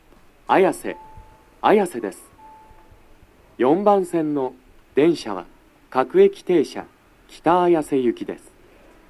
足元注意喚起放送の付帯は無いですが、0番線以外は、先発の北綾瀬行き発車ホームの案内などの駅員放送が、大変被りやすいです
男声
到着放送1